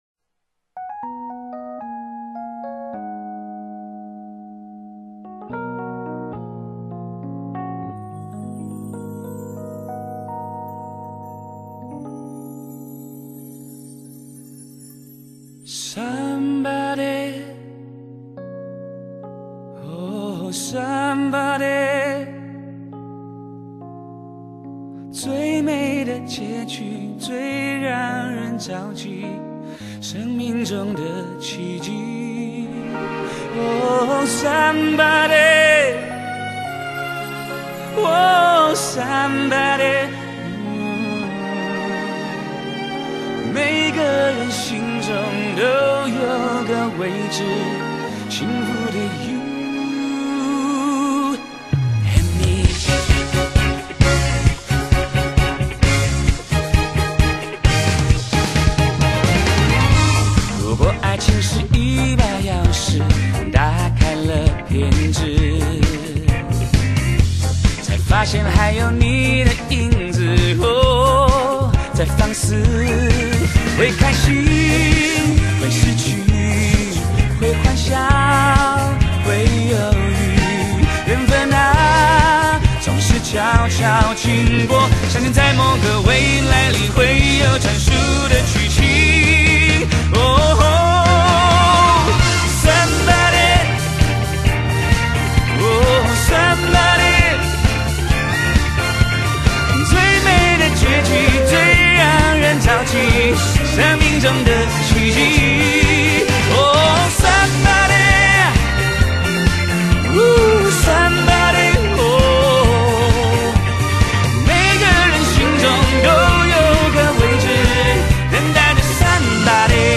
充滿情感的嗓音用心的歌唱  唱進我們的心坎裡